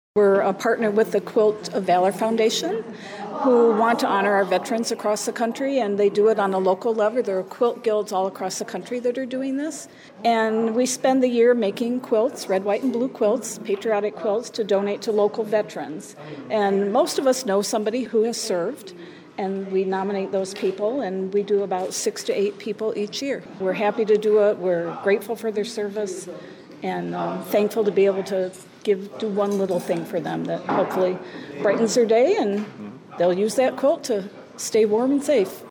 The gathering took place at Hidden River North Club House in Adrian.